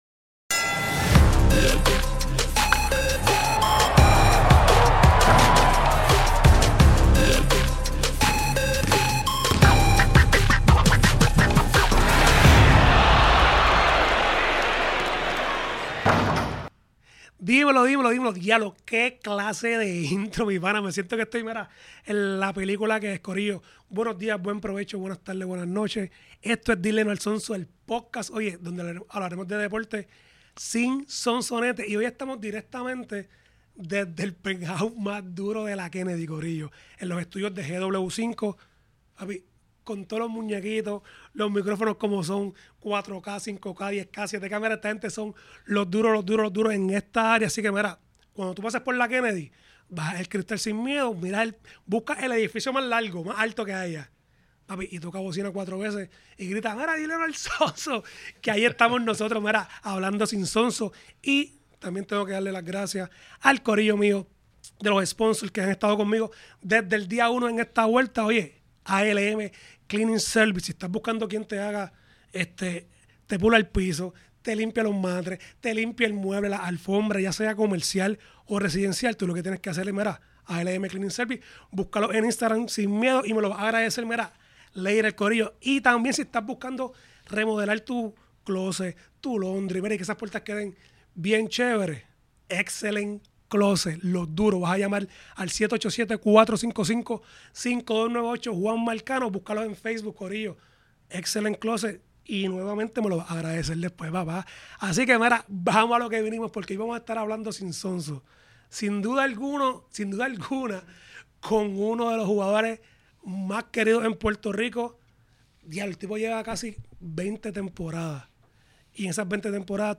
"Fili" nos cuenta desde como comenzó a jugar basket, practicar con los Cleveland Cavaliers y Dallas Mavericks y nos dice si se retira o no del BSN después de 19 temporadas. Grabado en los estudios de GW-Cinco para GW5 Network.